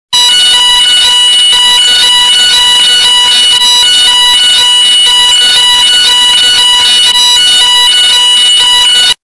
Sound Effects
Very Loud Agent Squad Fire Alarm